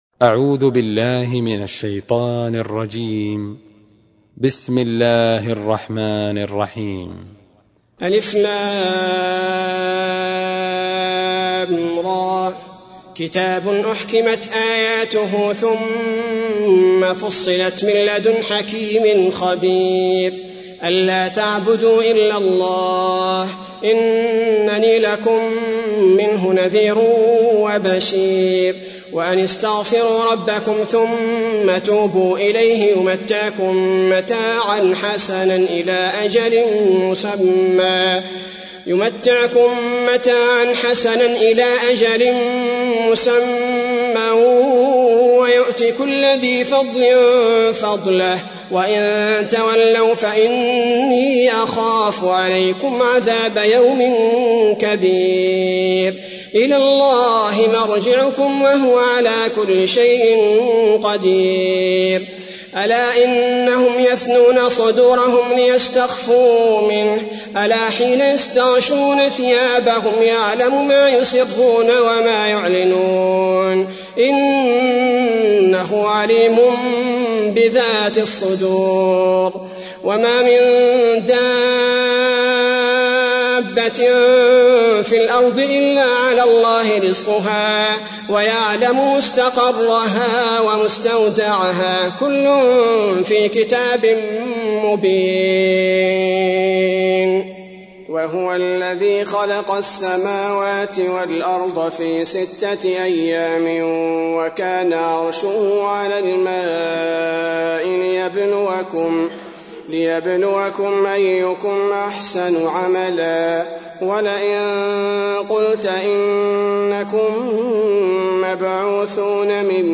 تحميل : 11. سورة هود / القارئ عبد البارئ الثبيتي / القرآن الكريم / موقع يا حسين